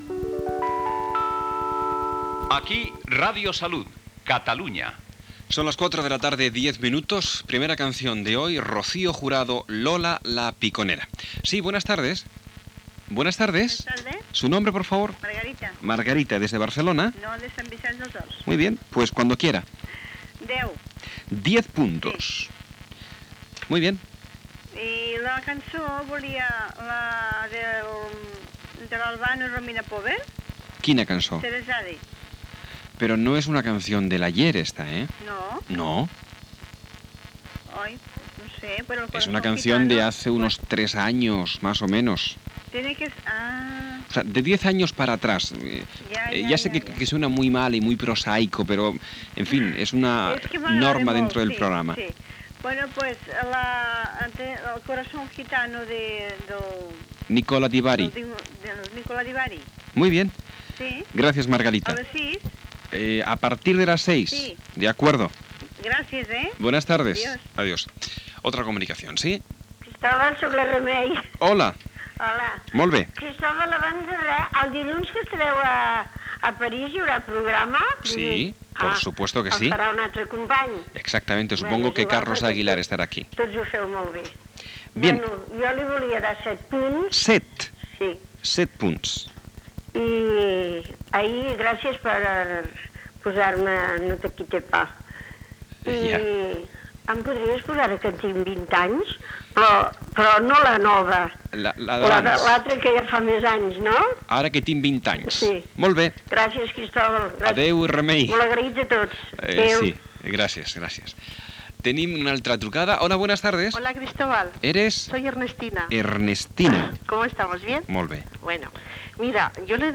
Indicatiu, hora, trucades telefòniques per votar i demanar cançons, publicitat, consell de Radio Salud, publicitat, tema musical.